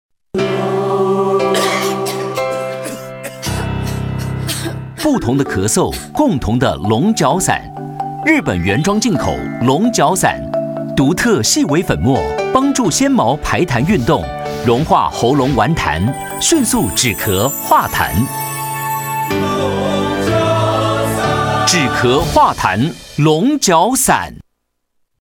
國語配音 男性配音員
廣告配音員